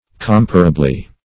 -- Com"pa*ra*ble*ness, n. -- Com"pa*ra*bly, adv.